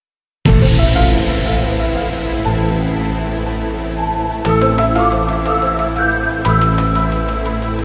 Catégorie: Bruitages